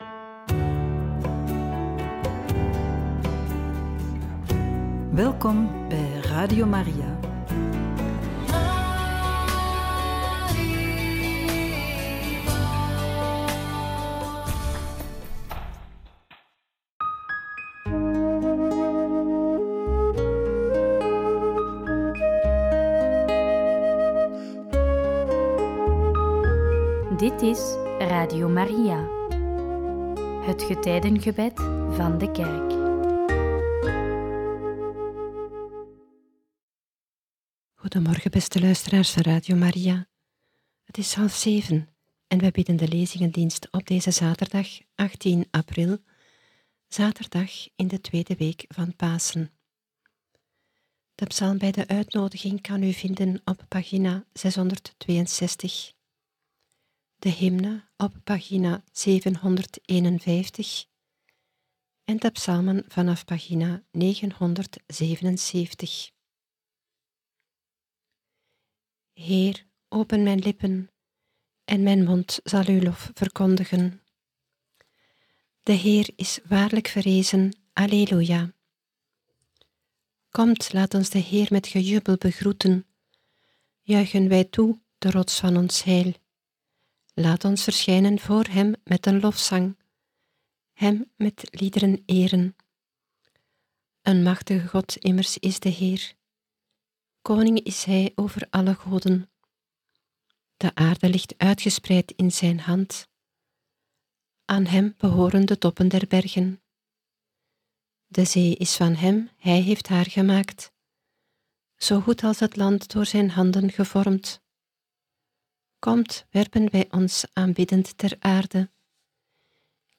Lezingendienst